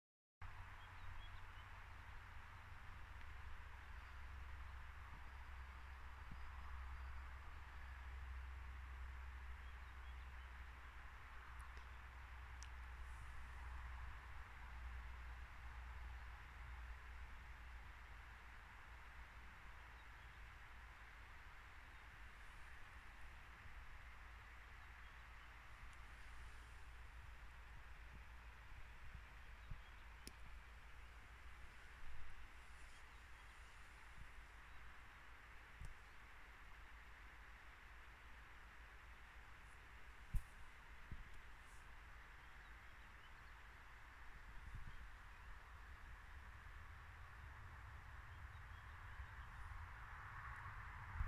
Ukjent fuglelyd
Det er ein nattfugl like ved huset vårt.
Dette er spillyden fra en enkeltbekkasin. Det er stjertfjærene som vibrerer når den stuper ned gjennom lufta. Også lyden i bakgrunnen i starten (chikk-a, chikk-a...) kommer fra en enkeltbekkasin som sitter på bakken.